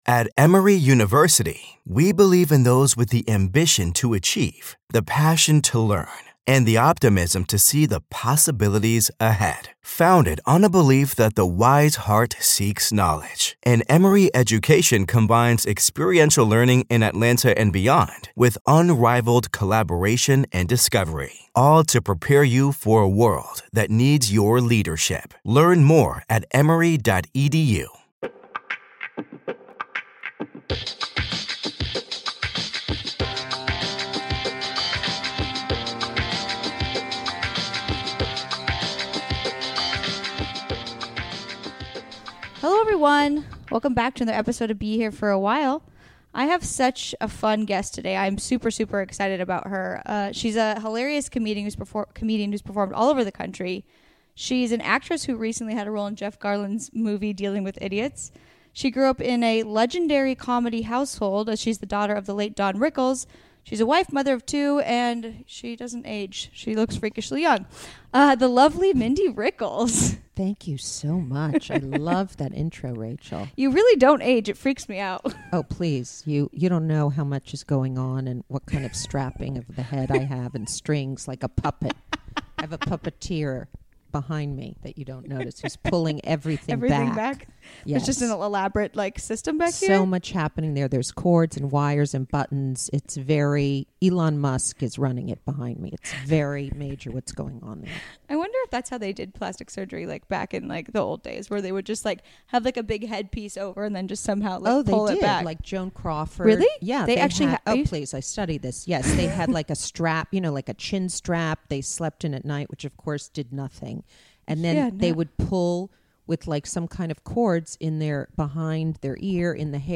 Education, Comedy, Comedy Interviews, Self-improvement